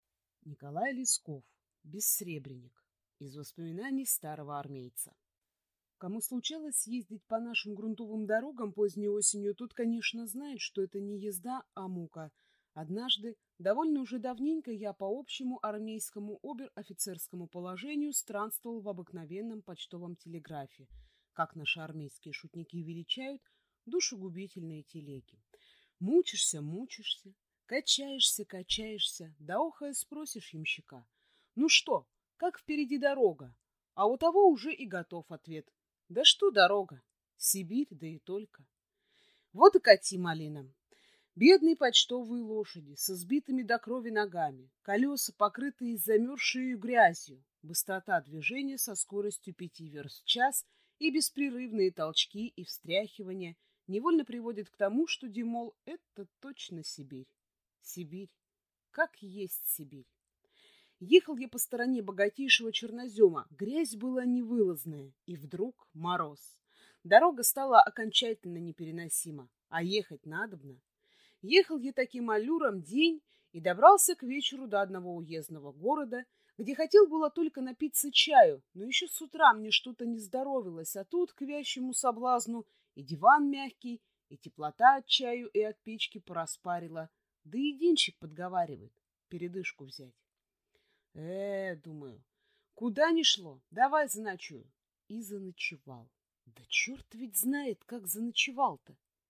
Aудиокнига Бессребреник